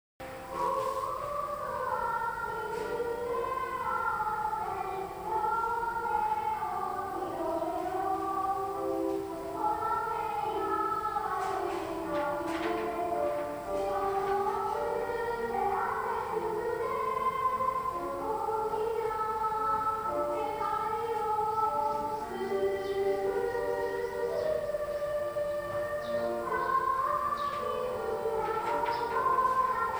そのため、放送委員会が給食時に校内放送で校歌の音楽を流しています。音源は以前録音したものです。
給食放送　校歌.mp3